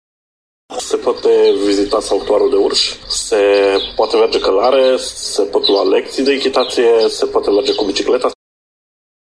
Instructor echitaţie